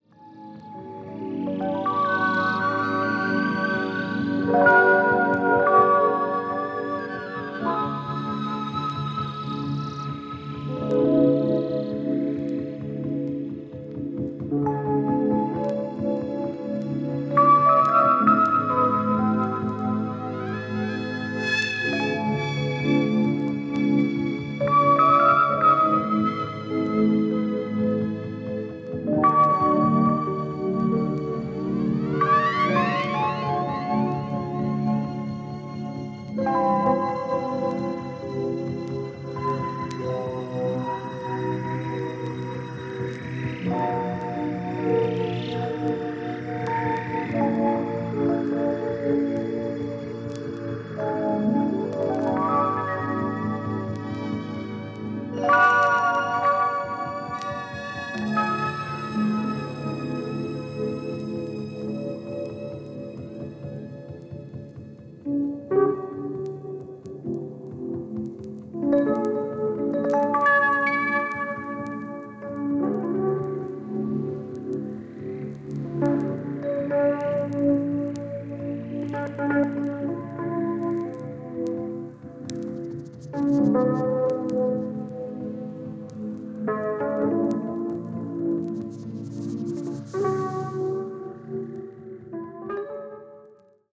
心地よく鳴り響きます。